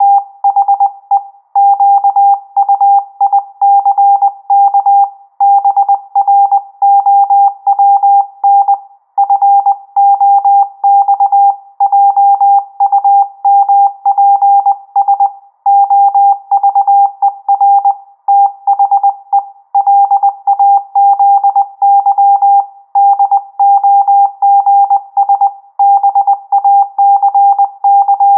La velocità di trasmissione è 20 wpm (parole al minuto), corrispondenti a 100 caratteri al minuto.
Probabilmente si tratta di riverbero naturale, presumo quindi che solo questi operatori abbiano registrato i suoni prodotti dall'altoparlante attraverso un microfono.
Op A 800 Hz.mp3